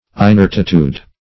Inertitude \In*ert"i*tude\